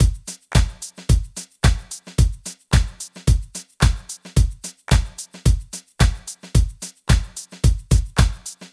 13 Drumloop.wav